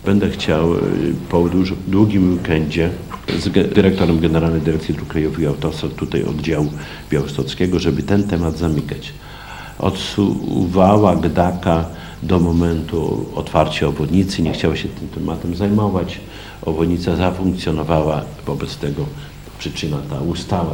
Powstanie łącznika pozwoliłoby zmniejszyć ruch ciężkich pojazdów na terenie miasta.  O szczegółach mówił na niedawnej konferencji prasowej Czesław Renkiewicz, prezydent Suwałk.